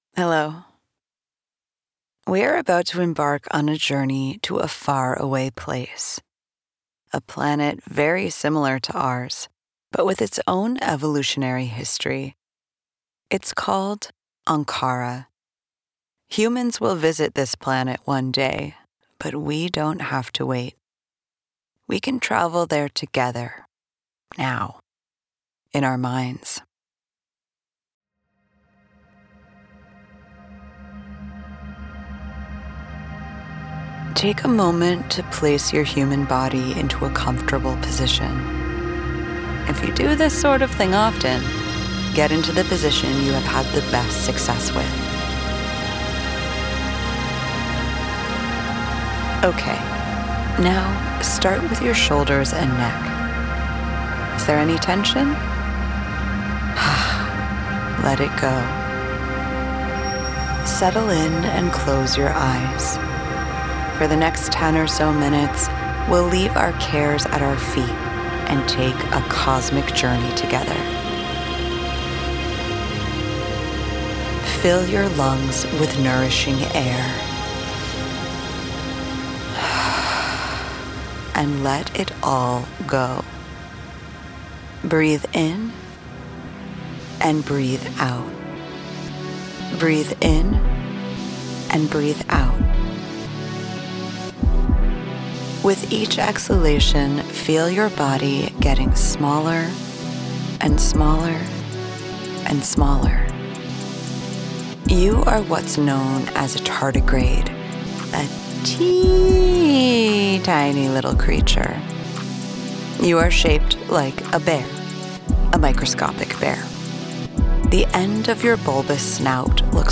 The first soundscape you’ll hear is a sonification of the Cat’s Eye nebula by NASA.